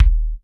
Kick_09.wav